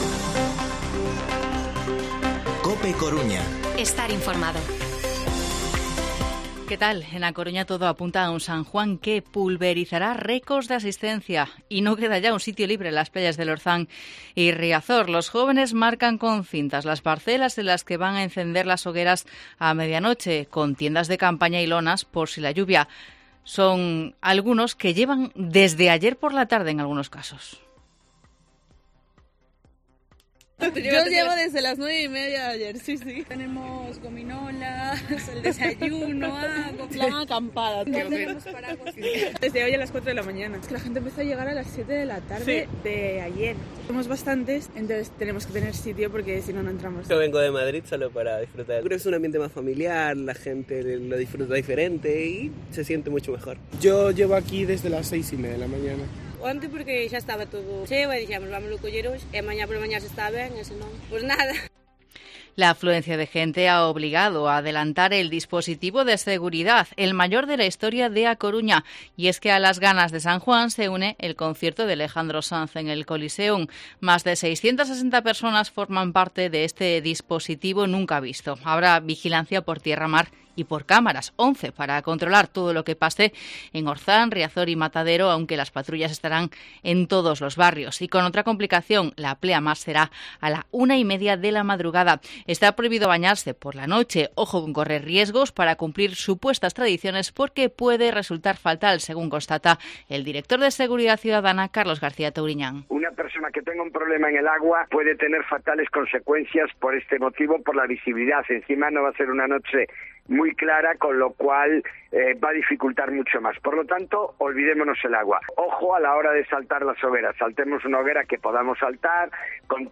Informativo Mediodía COPE Coruña 23 de junio de 2022 14:20-14:30